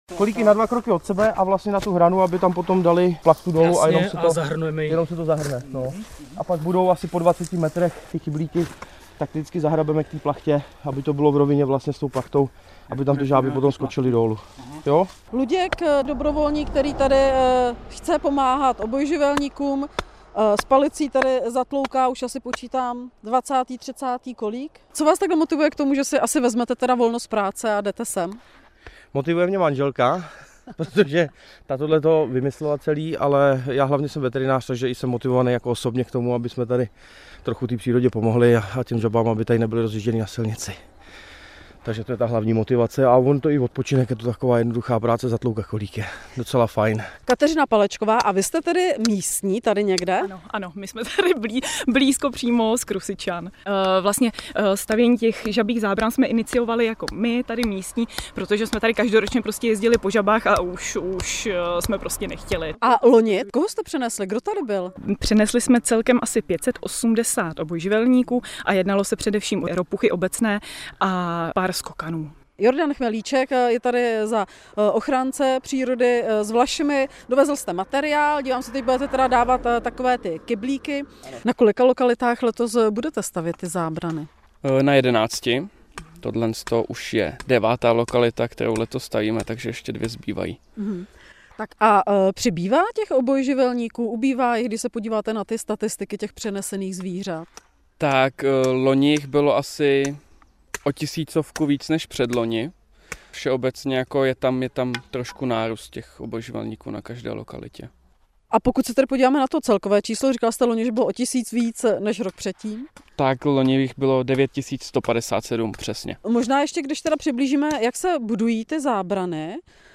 Zprávy Českého rozhlasu Střední Čechy: Jarní tah obojživelníků začal.